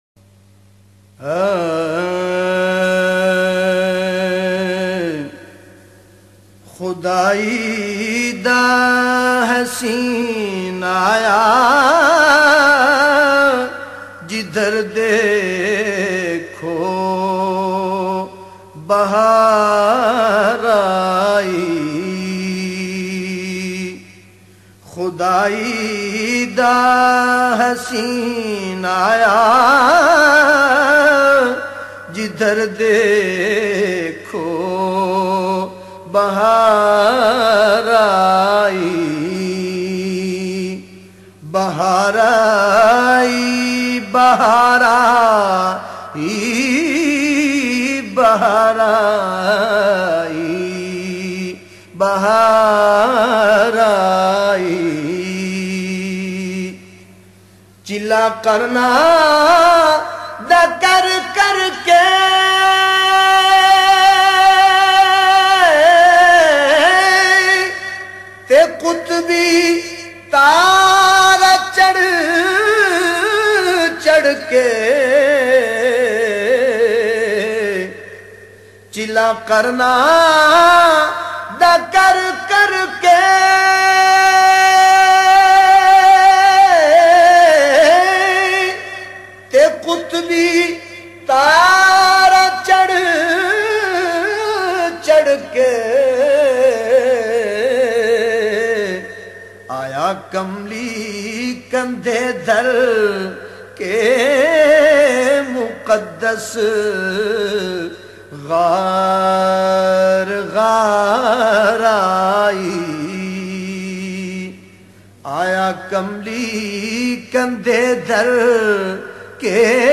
Bahaar Aai Naat mp3